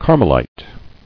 [Car·mel·ite]